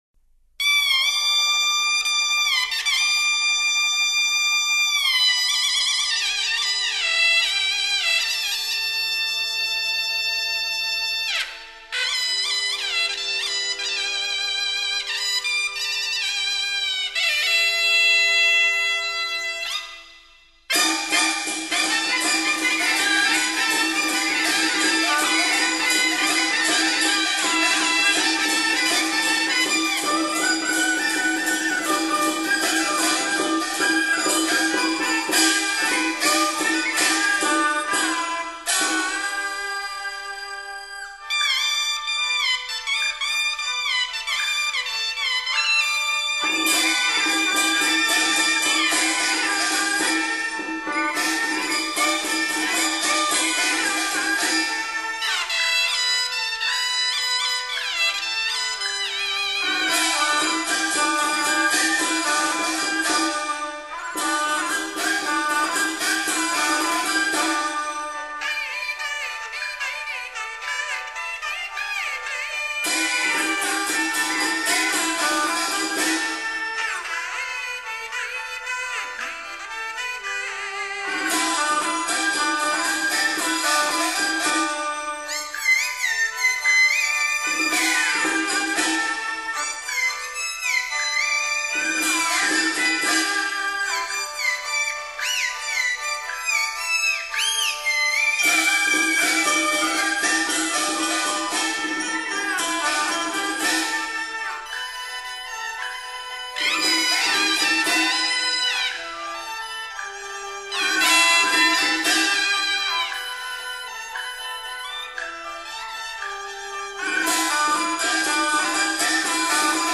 吹咔乐